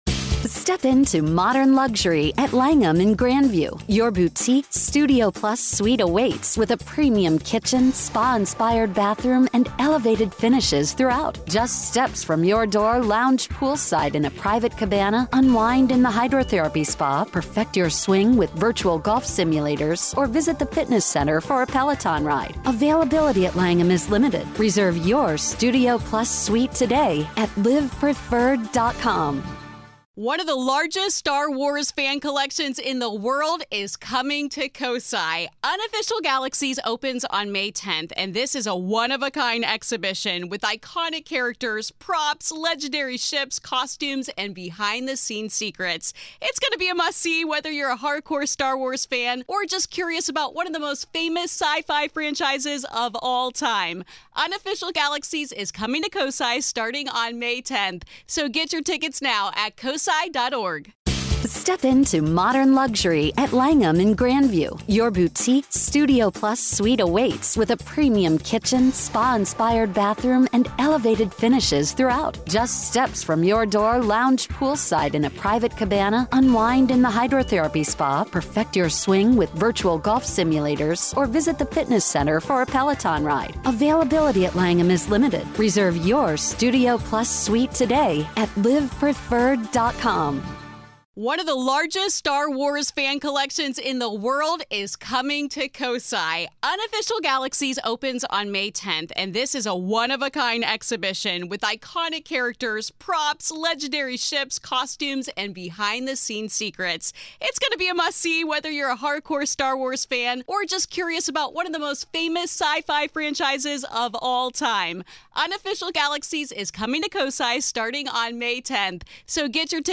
Today on the Grave Talks, what is haunting the Horridge House? A conversation